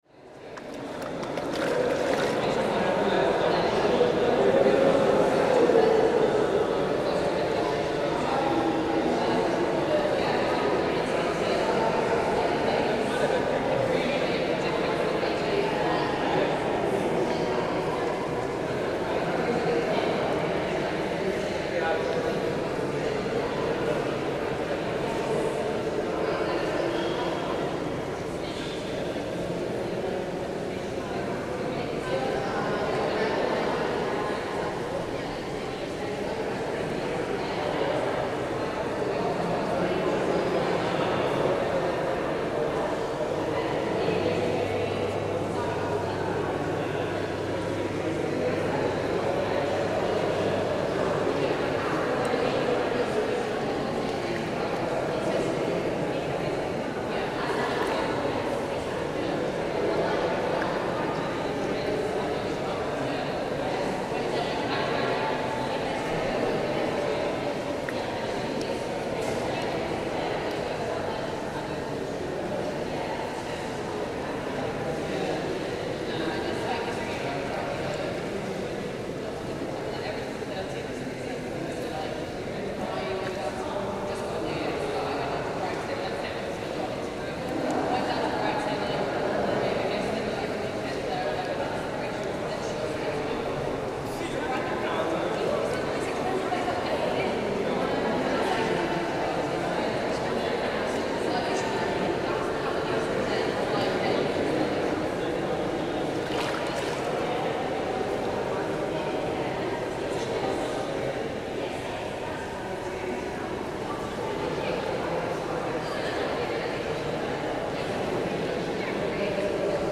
In a thermal pool, Gellert Baths